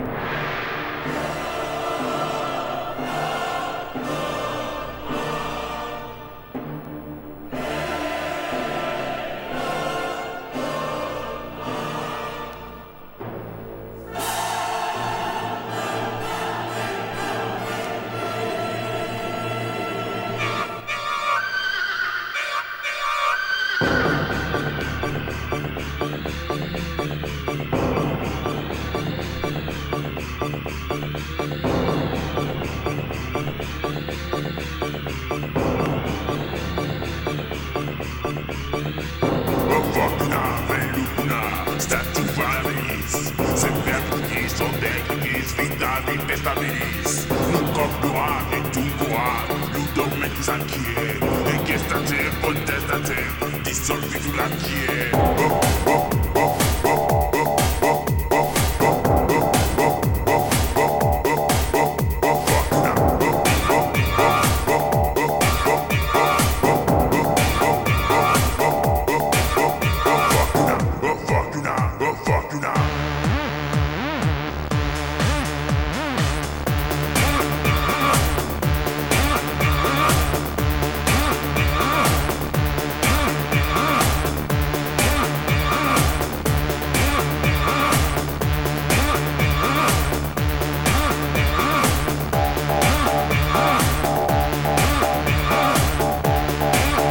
荘厳なアレンジとデスヴォイスがマッチした